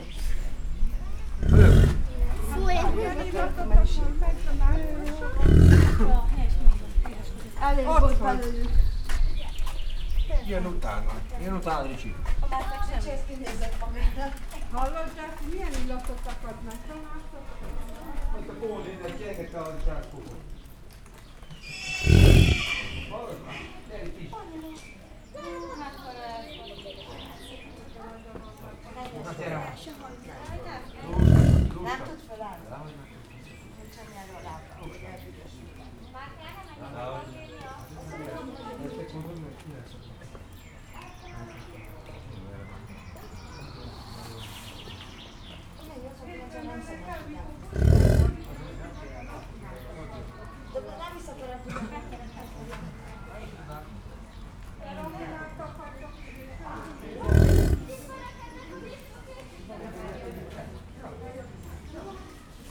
Directory Listing of /_MP3/allathangok/pecsizoo2017_professzionalis/amerikaiboleny/
kommentelok_hatszormegszolal01.02.WAV